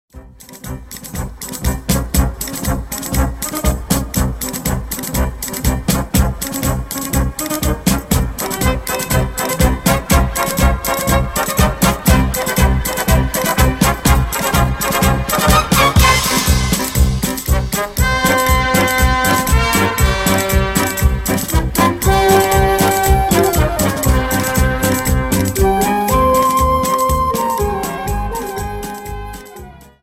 Dance: Paso Doble 60